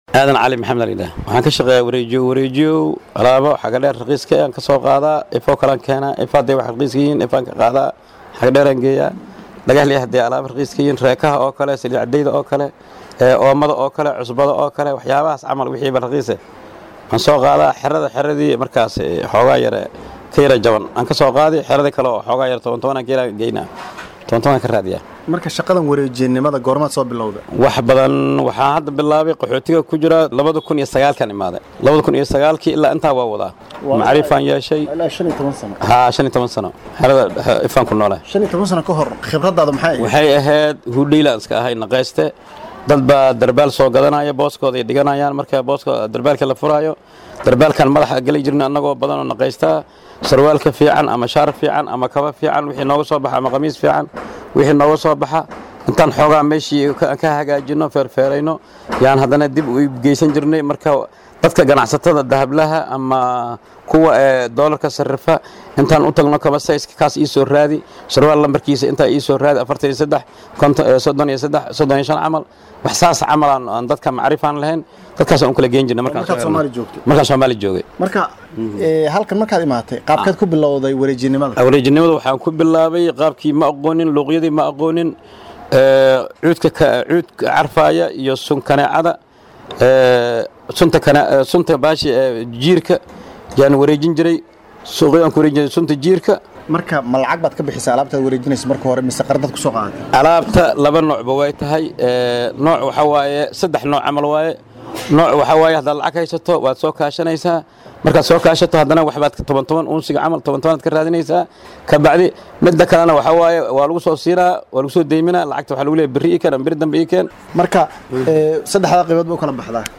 DHAGEYSO:Wareysi ku saabsan dulqaadka uu u baahan yahay qofka badeecooyinka wareejiya